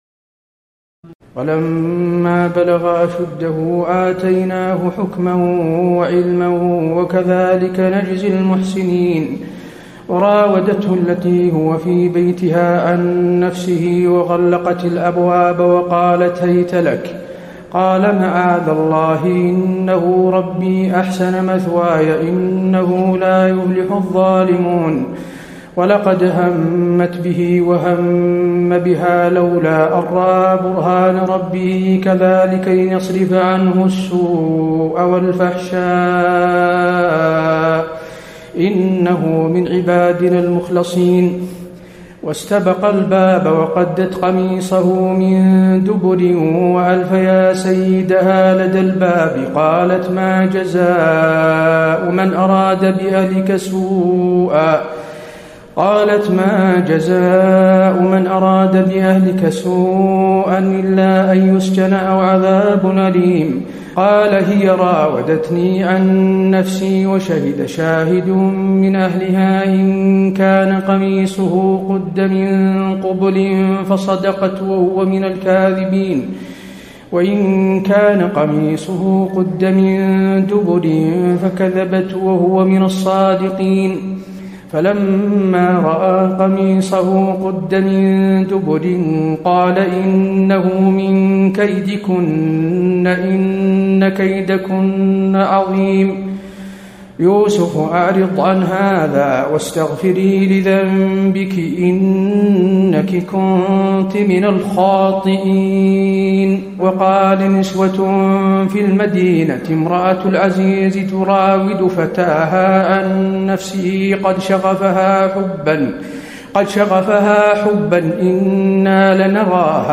تراويح الليلة الثانية عشر رمضان 1434هـ من سورة يوسف (22-111) Taraweeh 12 st night Ramadan 1434H from Surah Yusuf > تراويح الحرم النبوي عام 1434 🕌 > التراويح - تلاوات الحرمين